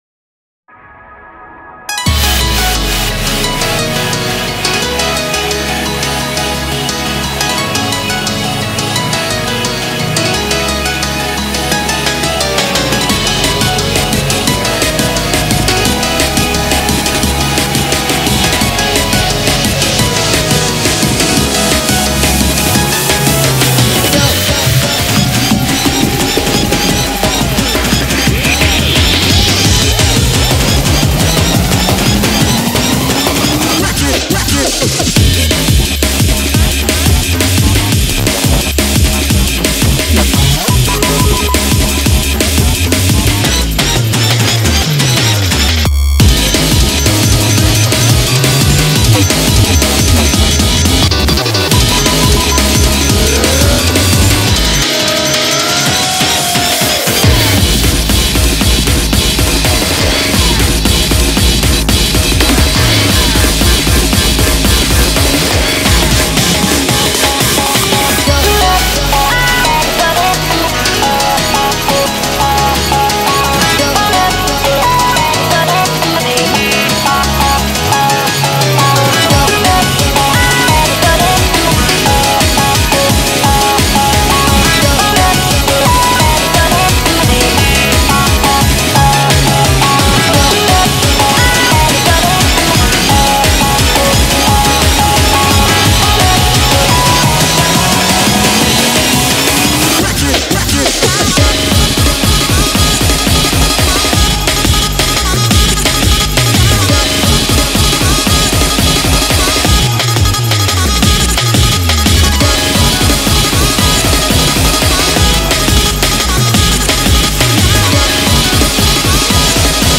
BPM174
Comments[NEO JUNGLE]